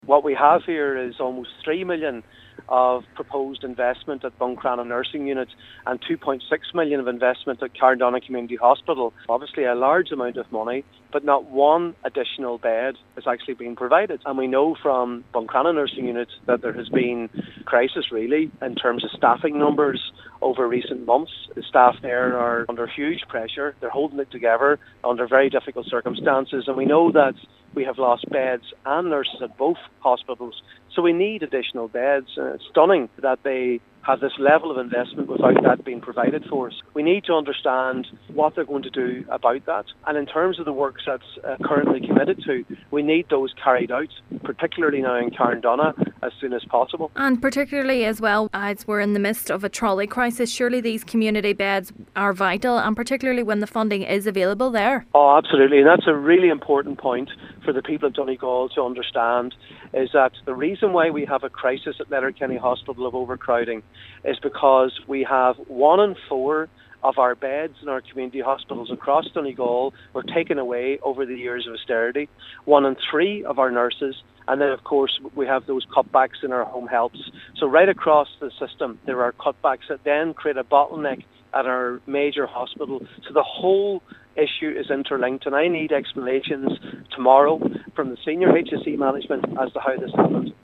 Senator Padraig MacLochlainn says the delay is attributing towards the growing trolley crisis: